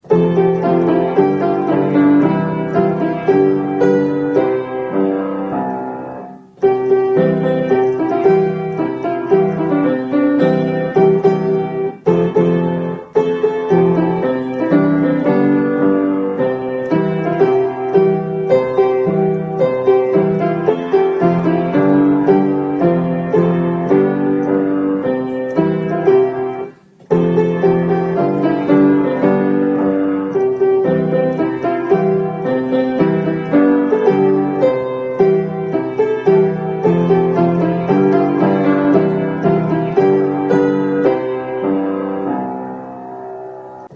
112尚仁國小校歌伴奏.mp3